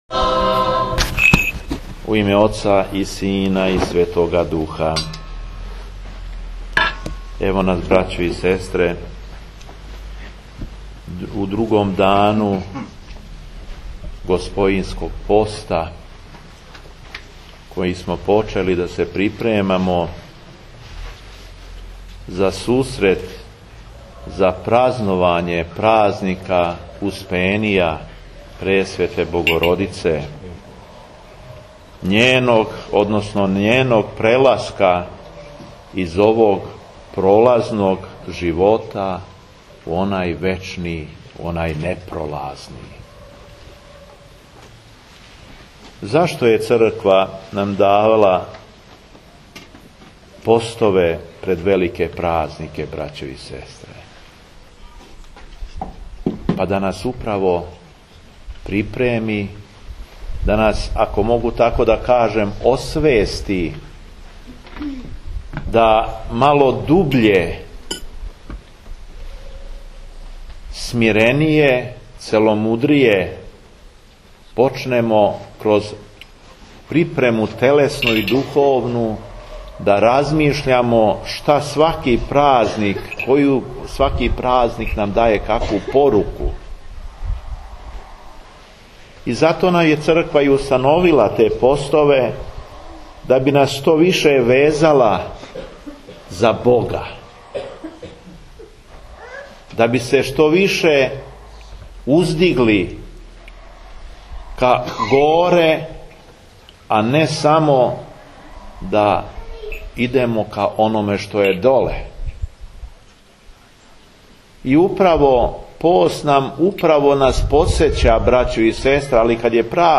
Беседа епископа шумадијског Г. Јована
Поводом славе капеле манастира Никоља, преноса моштију Светог архиђакона Стефана, 15. августа 2014. године Његово Преосвештенство Епископ шумадијски Господин Јован служио је Свету Архијерејску Литургију у летњиковцу манастирске порте.